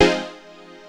HOUSE 11-L.wav